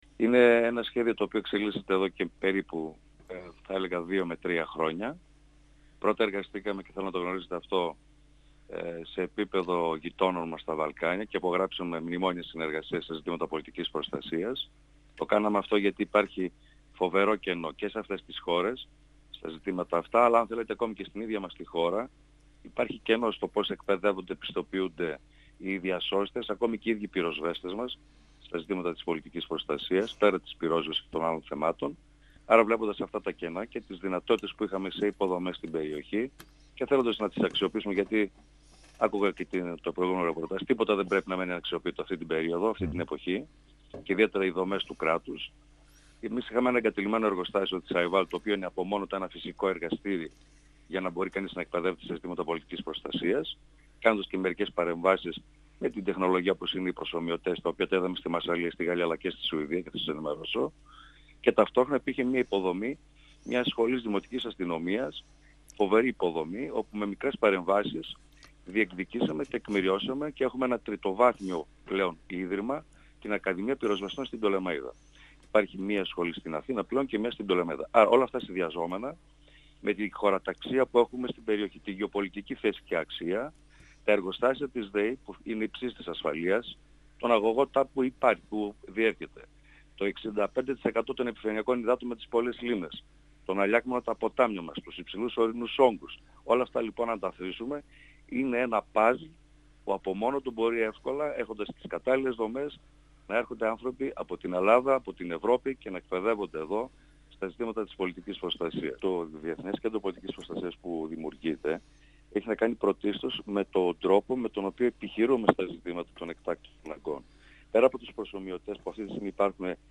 Ο Θεόδωρος Καρυπίδης, στον 102FM του Ρ.Σ.Μ. της ΕΡΤ3
Συνέντευξη